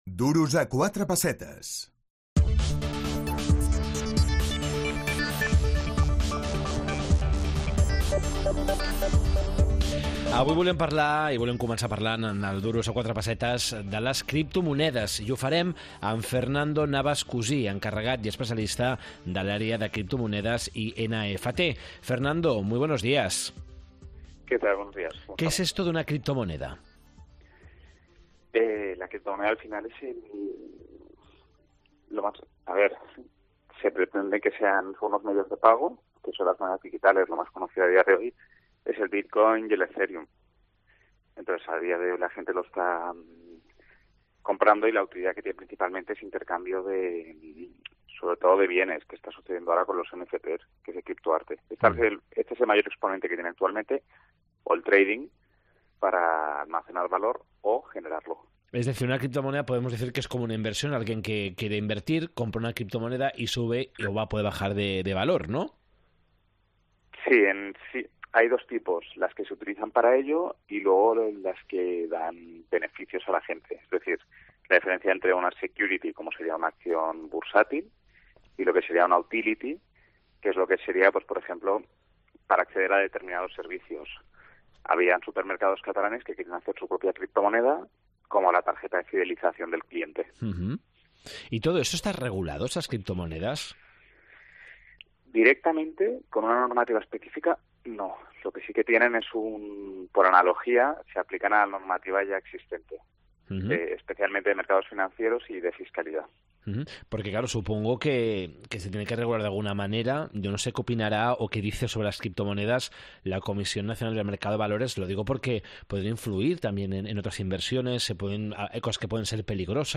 Las criptomonedas | Entrevista